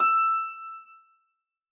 Harpsicord
e6.mp3